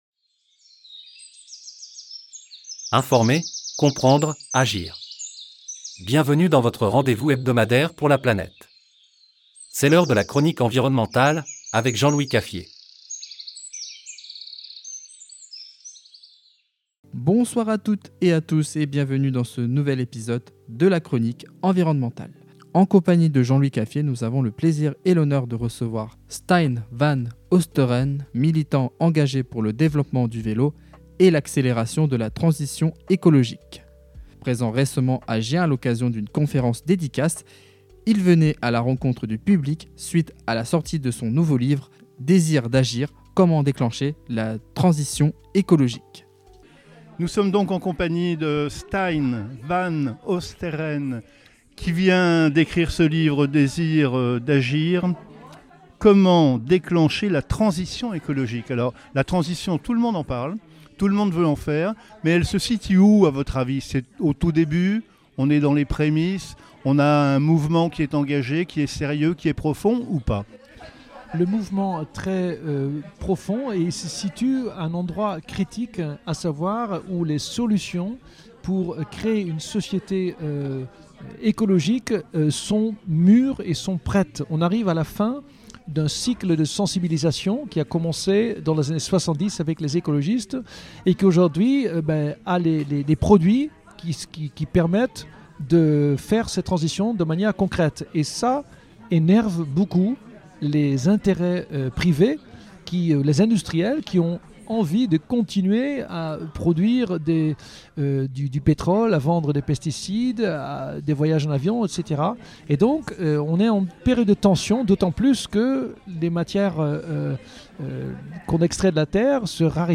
La Chronique Environnementale - interview